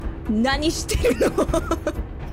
Cute
giggle nanishteno.mp3